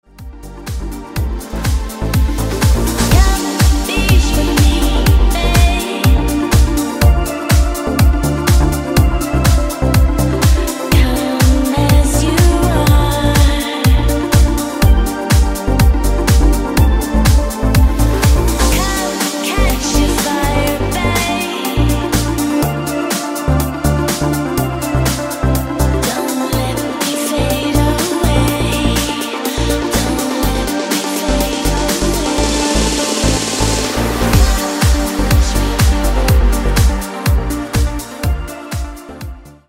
• Качество: 160, Stereo
deep house
dance
Electronic
спокойные
красивый женский голос